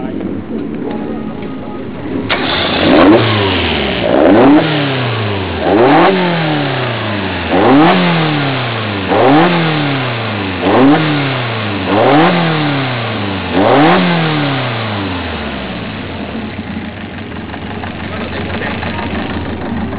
ZETECの咆哮は